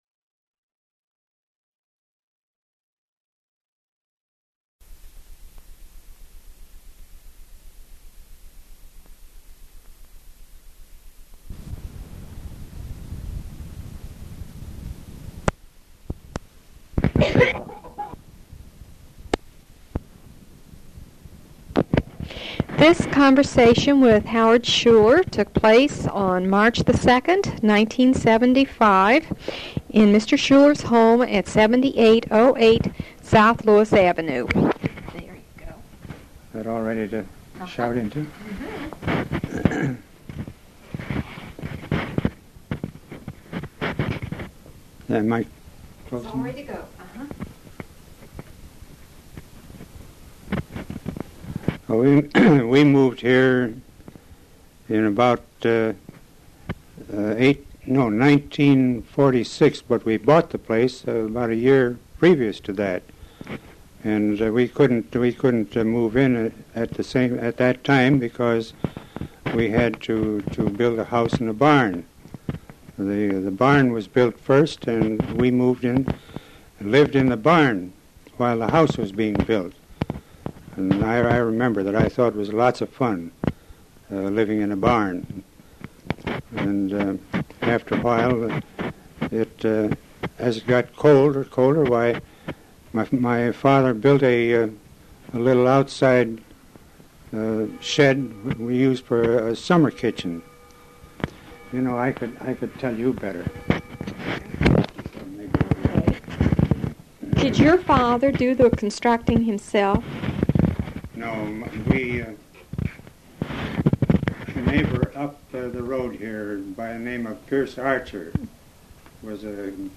Oral History
Interviews